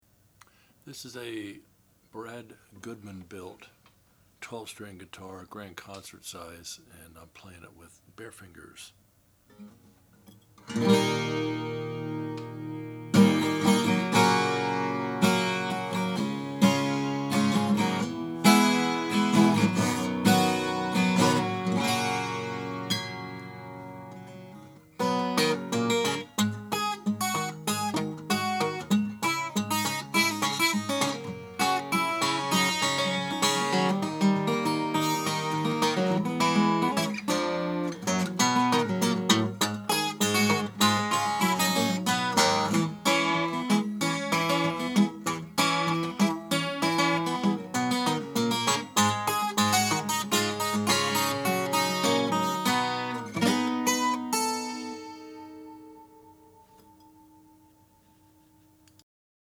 The back and sides are a fine grade of white oak, a traditional tone wood used by many early builders, and one that we find provides stellar tonal qualities.
Sonically, the notes are clearly defined across the spectrum with sustain that lasts forever, but not jangly like many modern 12s.